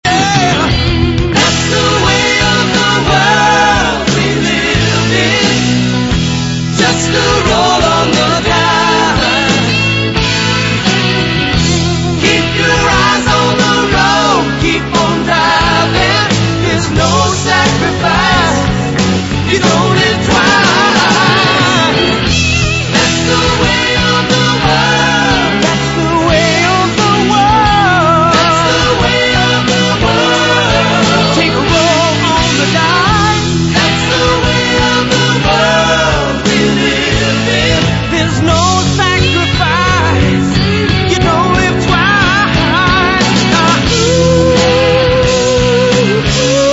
王道を行くテイストのメロディアス・ロック。